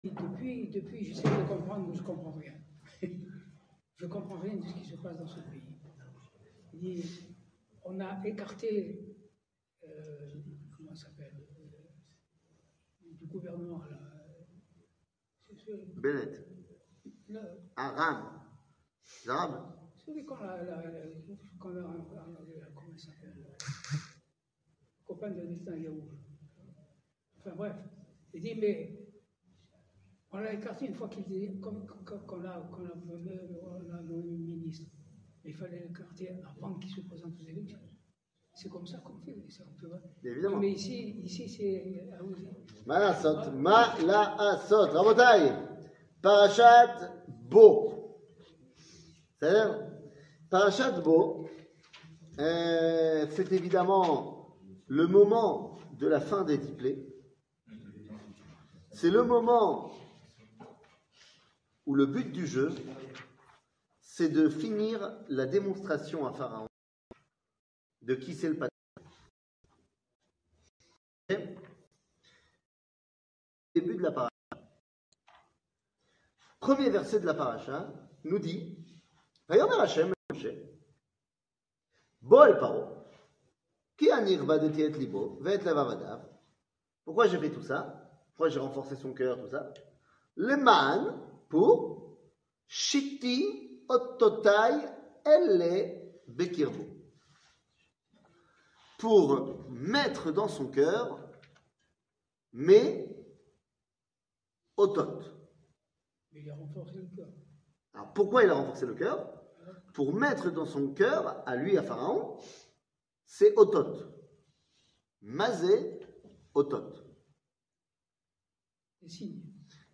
Parachat Bo, Le chien, la lune et le reste 00:39:03 Parachat Bo, Le chien, la lune et le reste שיעור מ 24 ינואר 2023 39MIN הורדה בקובץ אודיו MP3 (35.74 Mo) הורדה בקובץ וידאו MP4 (61.2 Mo) TAGS : שיעורים קצרים